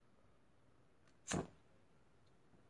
音频I " Chispas Fuego
Tag: 环境 atmophere 记录